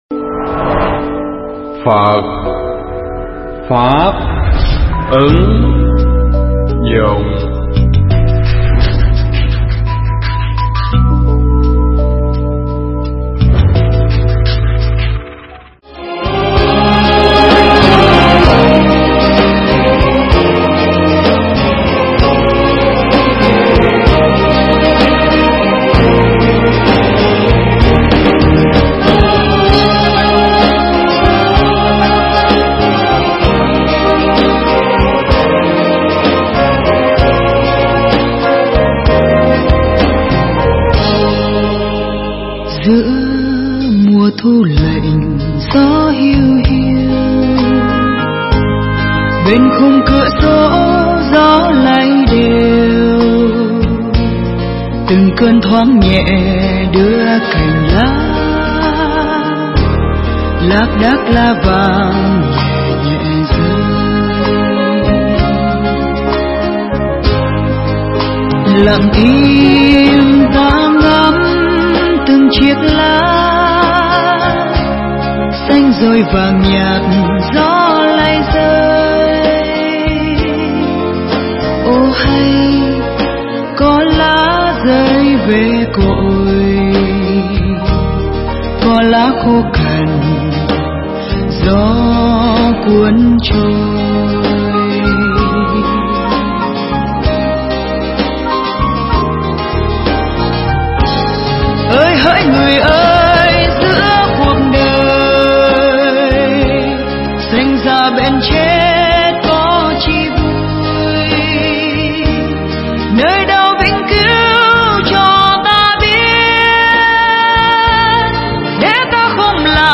Mp3 Thuyết pháp Phật Pháp Vấn Đáp 06
chùa Ấn Quang